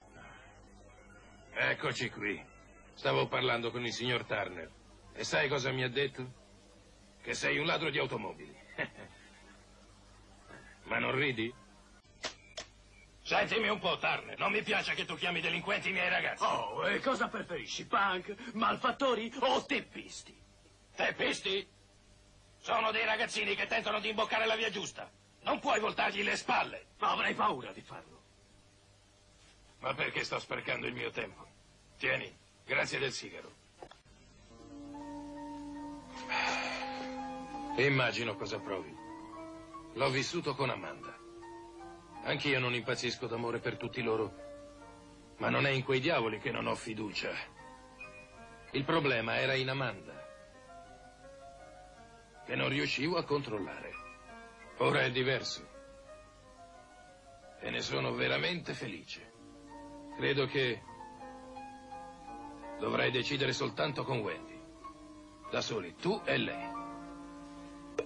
nel telefilm "La gang degli Orsi", in cui doppia Jack Warden.